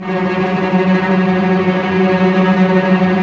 Index of /90_sSampleCDs/Roland LCDP13 String Sections/STR_Vcs Tremolo/STR_Vcs Trem f